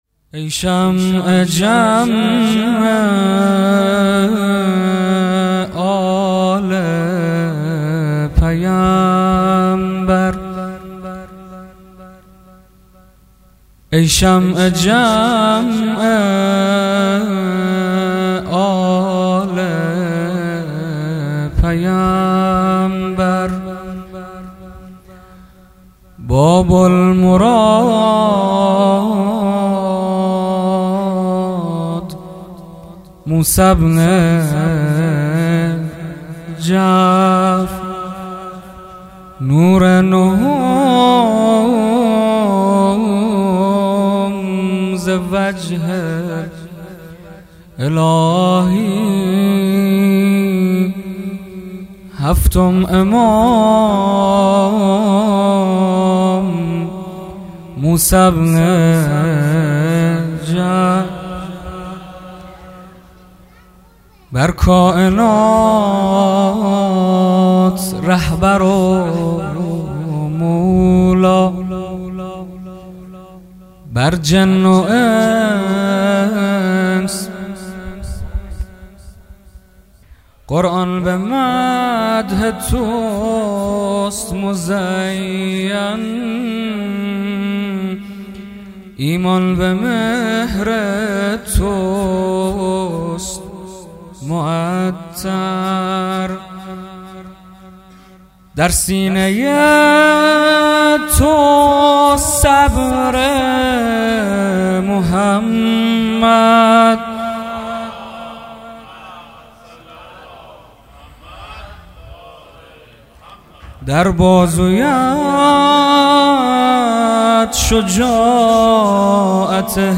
ولادت امام کاظم 97